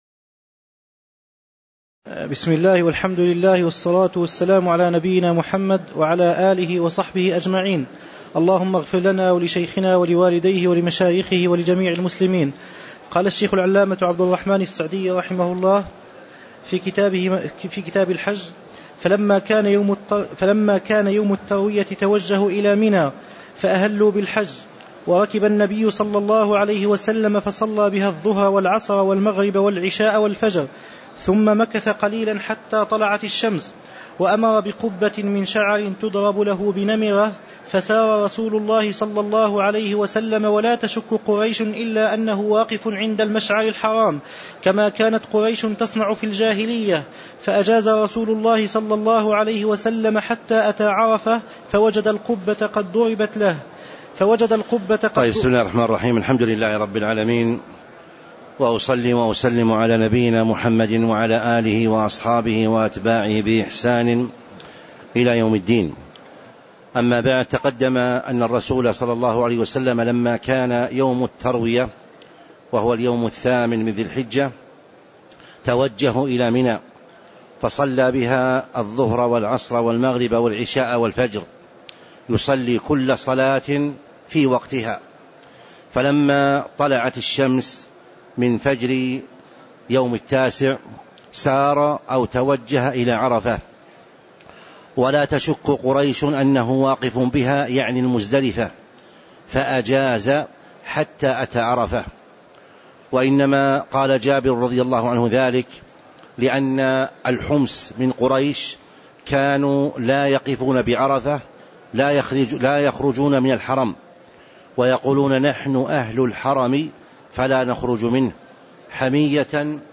تاريخ النشر ١٨ ذو القعدة ١٤٤٥ هـ المكان: المسجد النبوي الشيخ